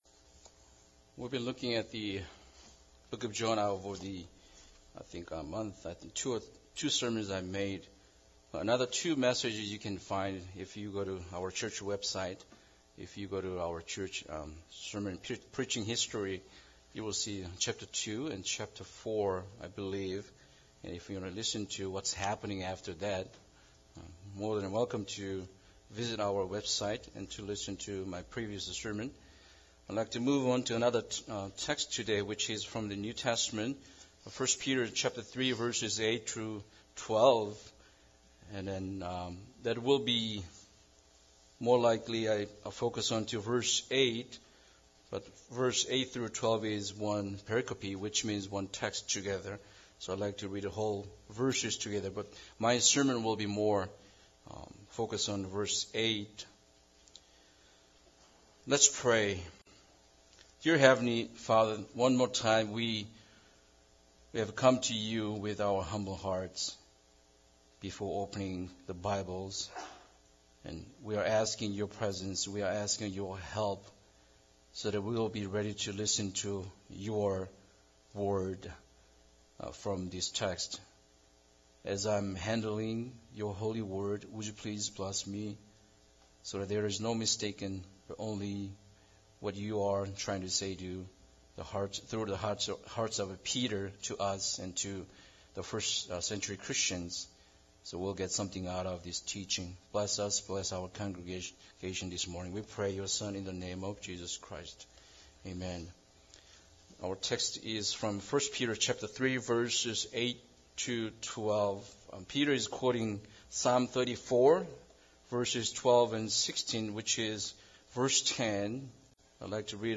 1 Peter 3:8-12 Service Type: Sunday Service Bible Text